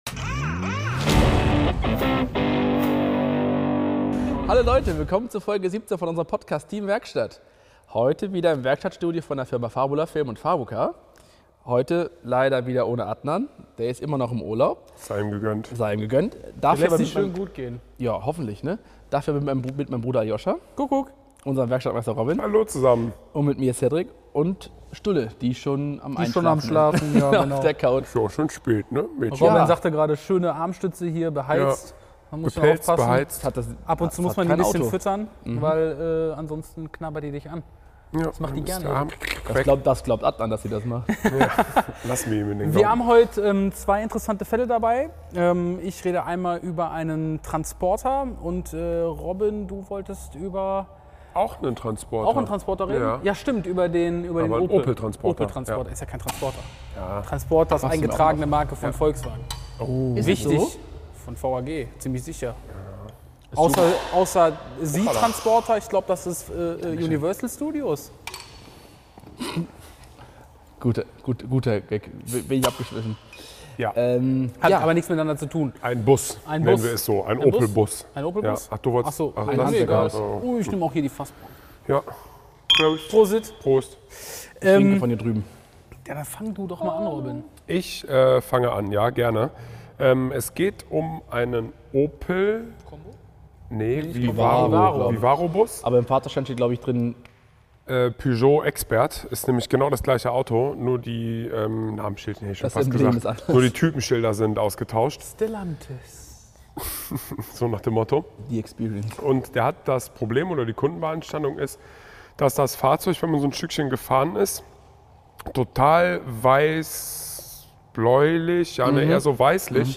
Die Folge 17 wurde wieder im neuen Werkstattstudio von Fabula Film und FabuCar in Schwelm aufgenommen.